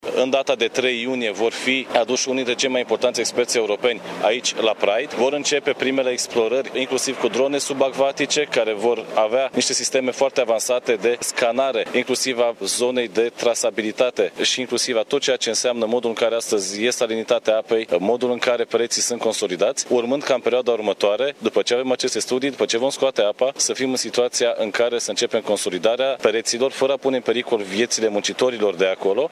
Specialiștii vor folosi drone subacvatice, a precizat ministrul Economiei, Bogdan Ivan.